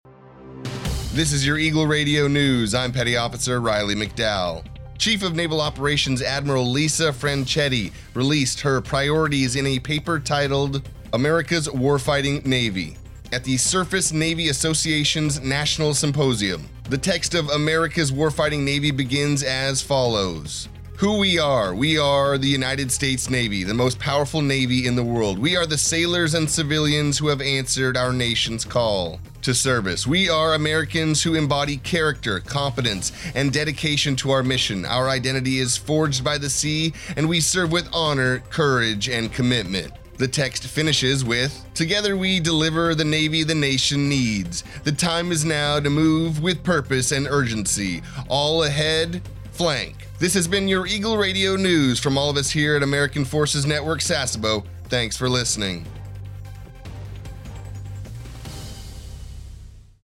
A TFNewscast for AFN Sasebo's radio about the Chief of Naval Operations Adm. Lisa Franchetti releasing her priorities in a paper titled "America's Warfighting Navy" at the Surface Navy Association's National Symposium.